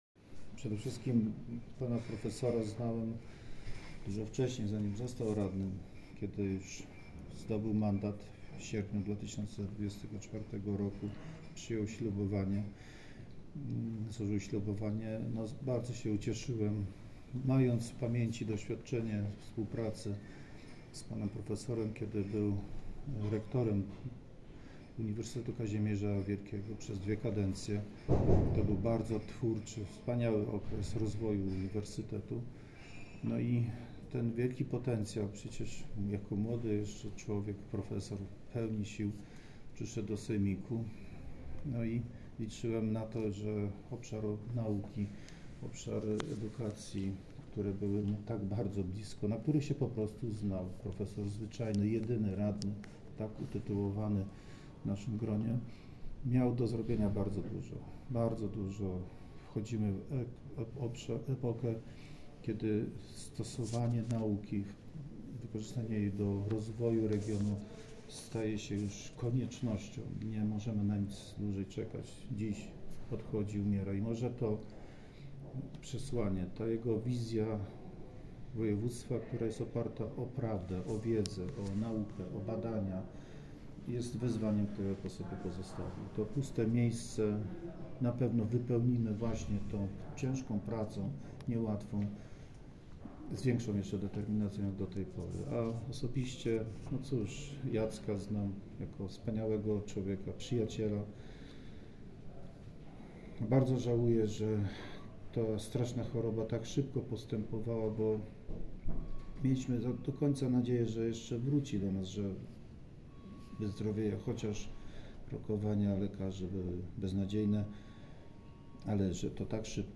Hołd zmarłemu niedawno prof. Jackowi Woźnemu, radnemu województwa obecnej kadencji, oddali dziś podczas uroczystego spotkania w Urzędzie Marszałkowskim radni i zarząd województwa.
2.01.2026 – marszałek Piotr Całbecki, pożegnanie Jacka Woźnego